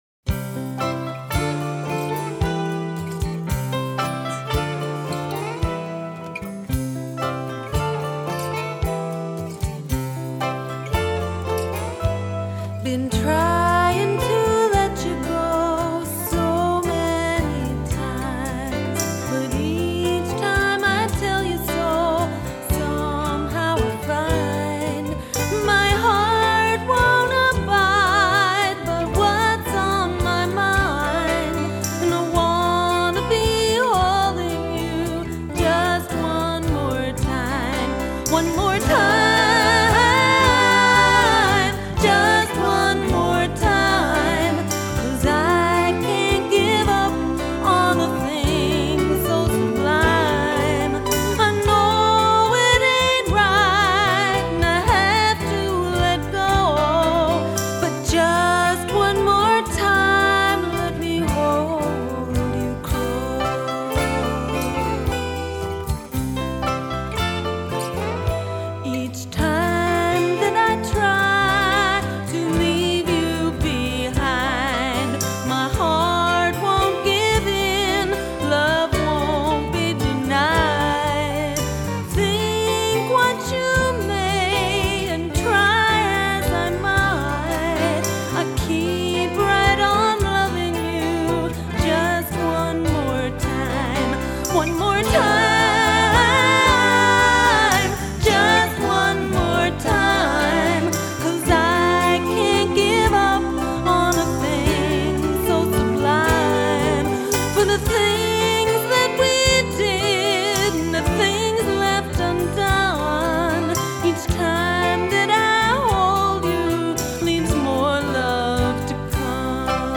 vocals, acoustic guitars, keyboard percussion
vocals, acoustic guitar and electric guitars
keyboards, keyboard percussion
bass
drums